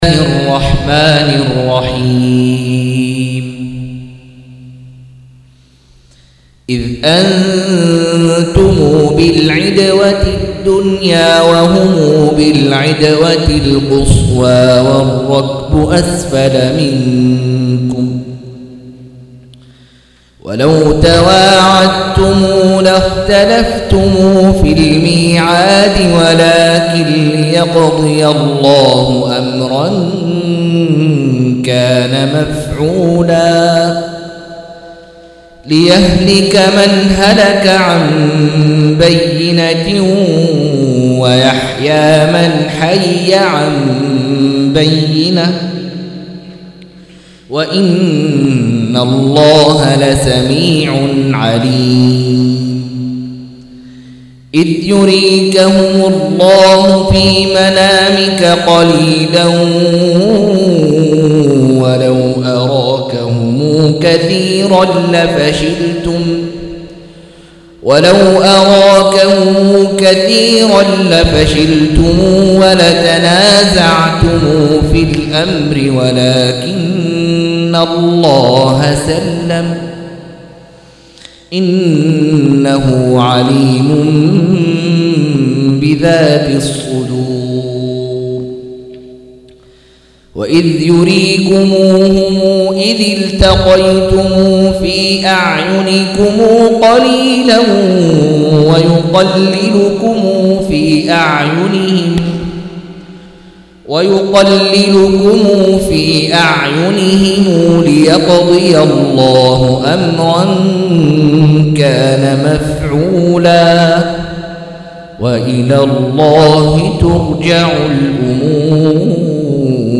172- عمدة التفسير عن الحافظ ابن كثير رحمه الله للعلامة أحمد شاكر رحمه الله – قراءة وتعليق –